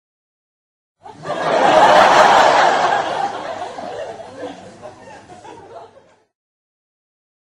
Sitcom Laugh Meme Effect sound effects free download